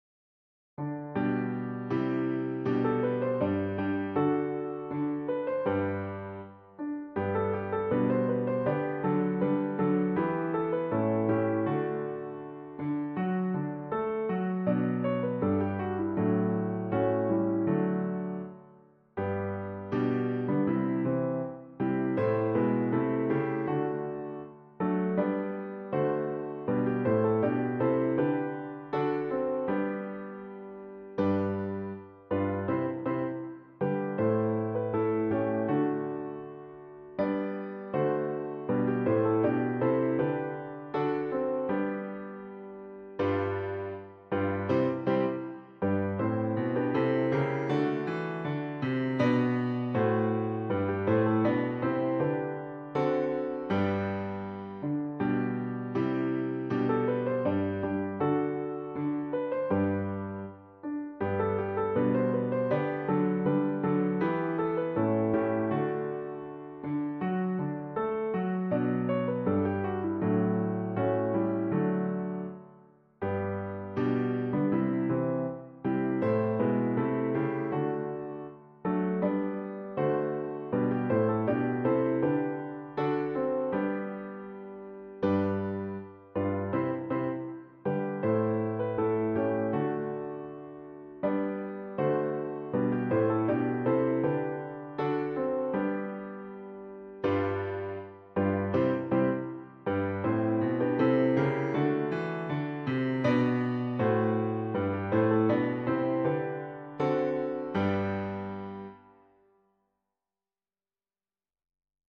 a Patriotic Song of Britain and England
for piano